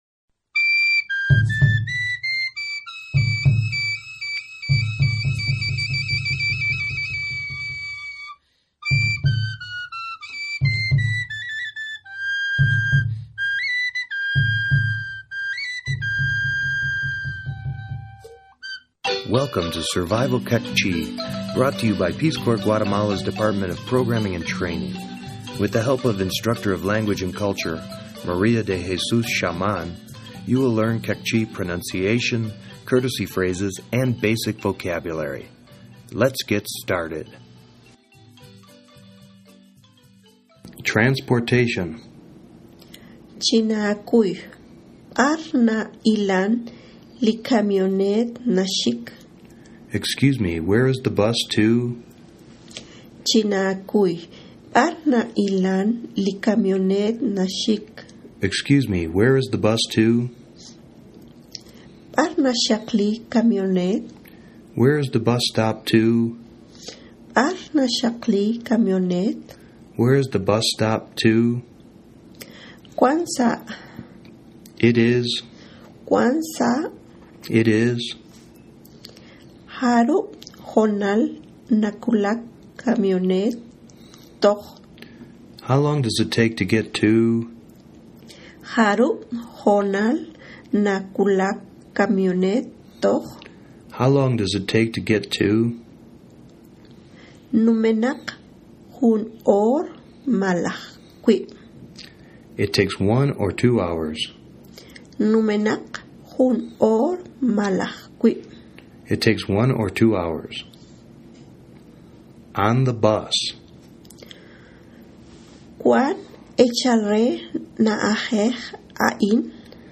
Survival Queqchi - Lesson 04 - Transportation, Formal Greetings_.mp3